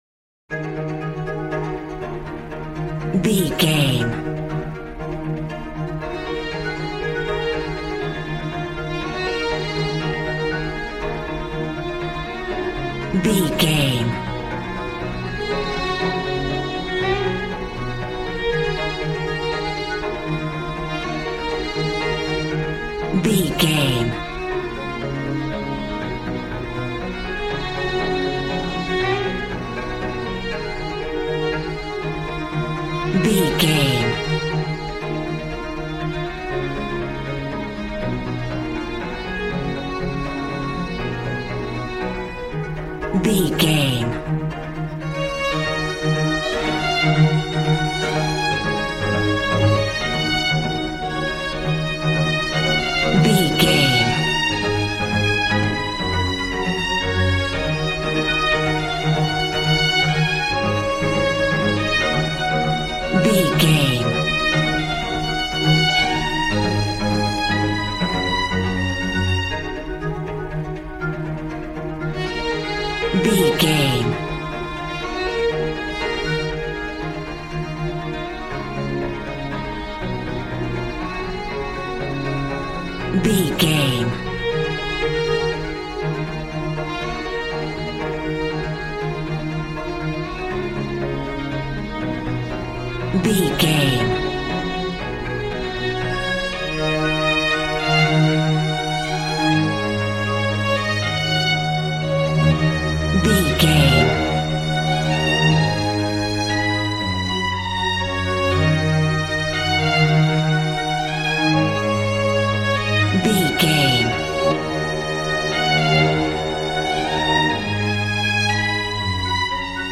Modern film strings for romantic love themes.
Regal and romantic, a classy piece of classical music.
Aeolian/Minor
E♭
regal
cello
violin
brass